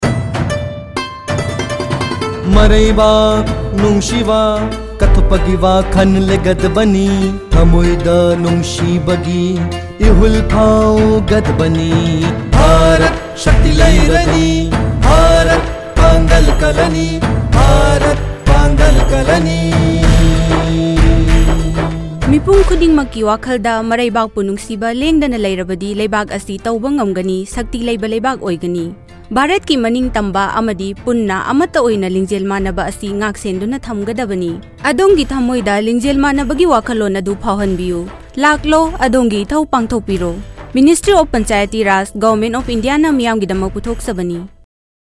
137 Fundamental Duty 3rd Fundamental Duty Protect sovereignty & integrity of India Radio Jingle Manipuri